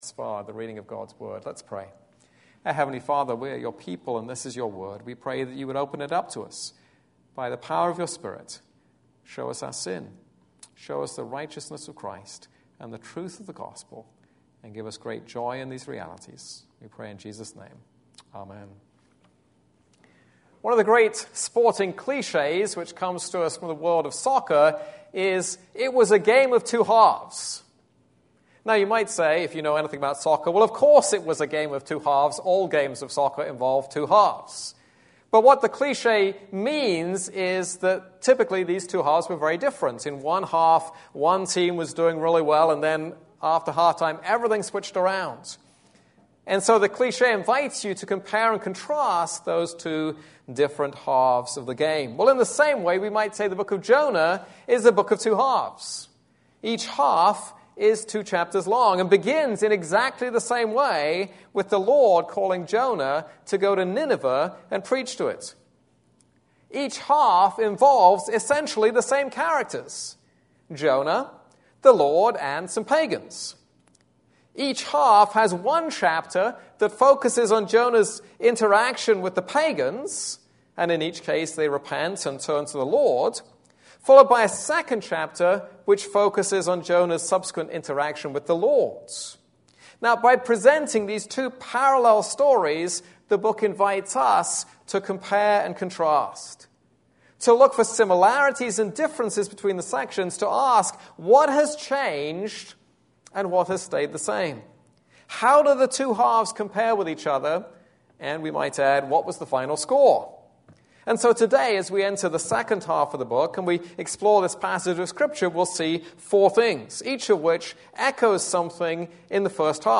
This is a sermon on Jonah 3.